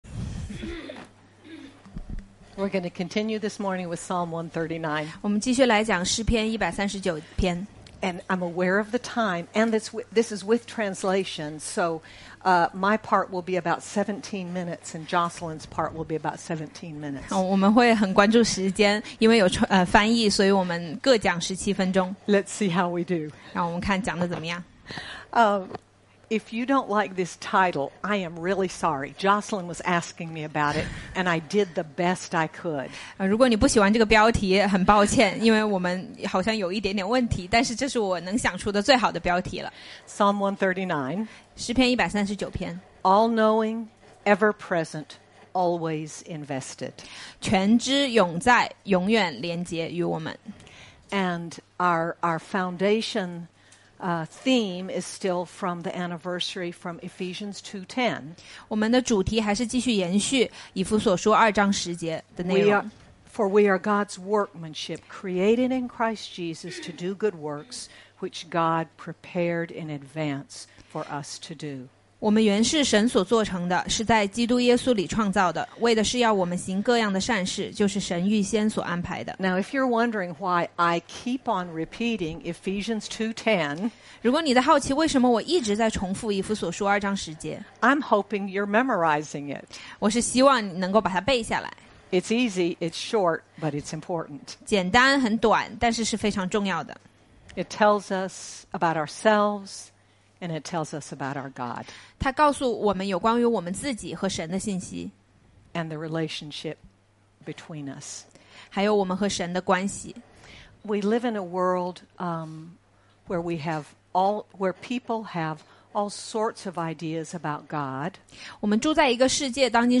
When we know this is our God, we can be confident to trust Him with all our lives. Sermon by